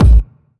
GS Phat Kicks 019.wav